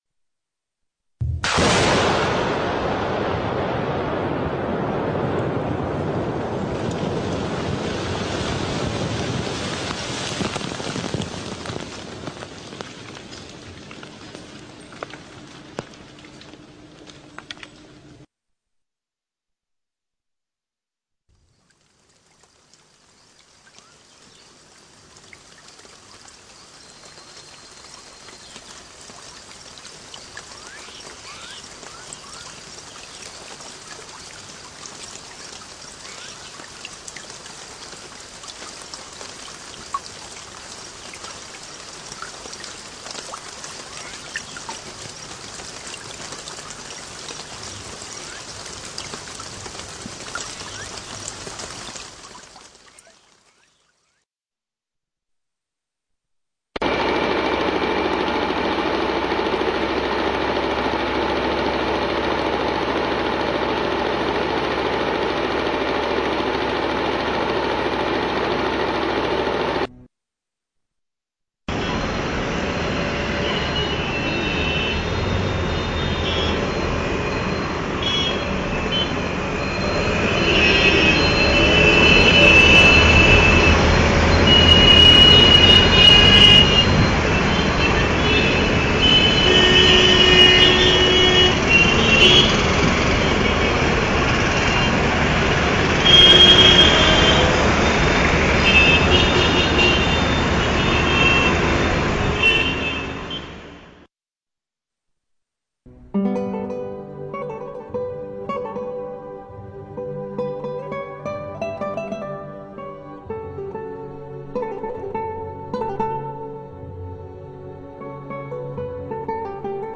L'audio propone sei effetti sonori (a - b - c - d - e - f).
decibel_vari.mp3